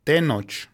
modern Nahuatl pronunciation) was a ruler of the Mexicas (Aztecas) during the fourteenth century during the Aztec travels from Aztlán to Tenochtitlan.